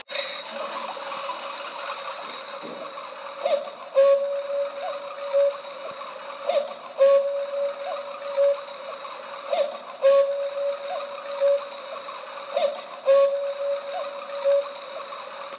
Cuckoo pendulum movement Contini Orologi
Description:Pendulum movement Cuckoo “TIME CONTROL”.
Cucu.m4a